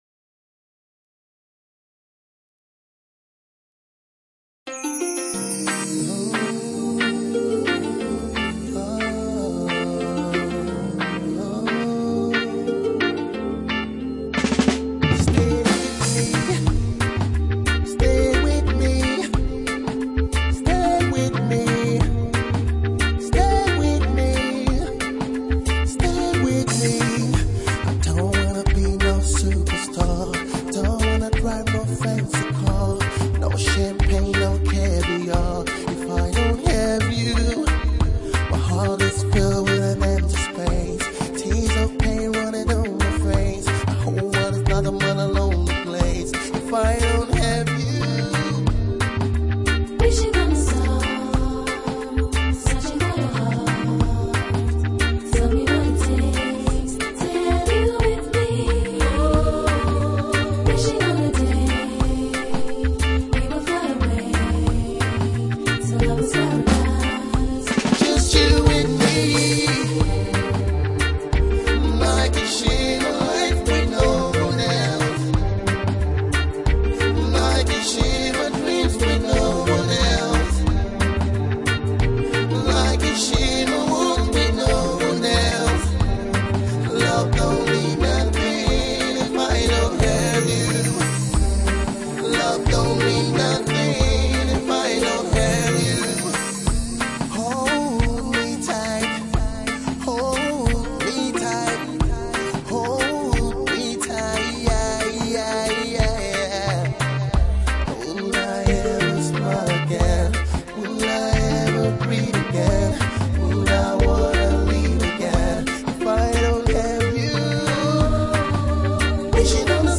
RnB Reggae inspired love song